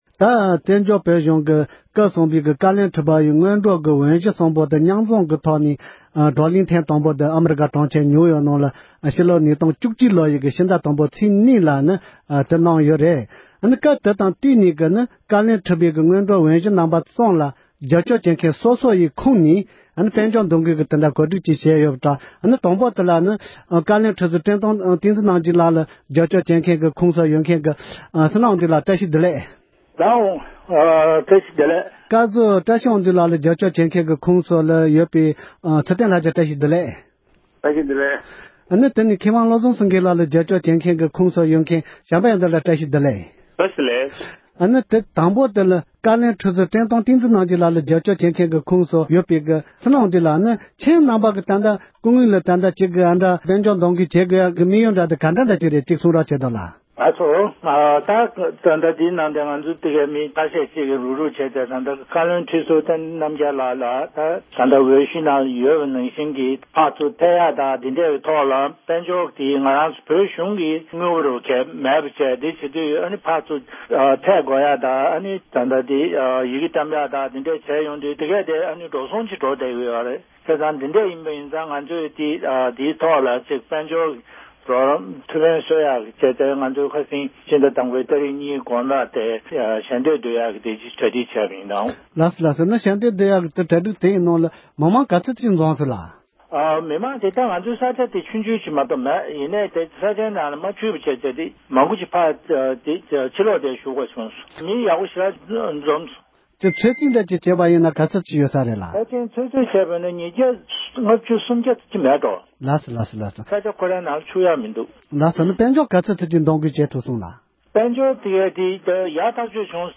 གླེང་མོལ་ཞུས་པ་ཞིག་ལ་གསན་རོགས༎